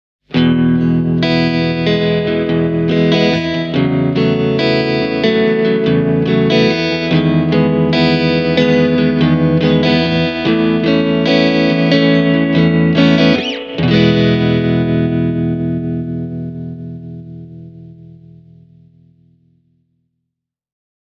Tässä on vielä yksi esimerkki, jossa hyödynnetään molempia efektejä yhtä aikaa:
gurus-amps-sinusoid-e28093-tremolo-plus-spring-reverb.mp3